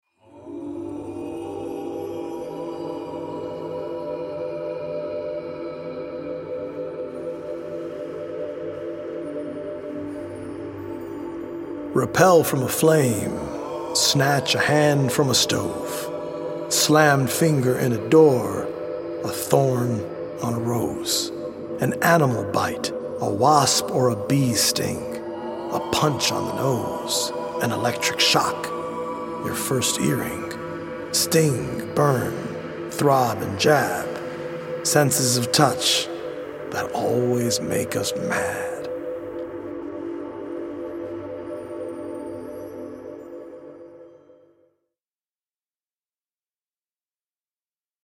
audio-visual poetic journey
healing Solfeggio frequency music
EDM producer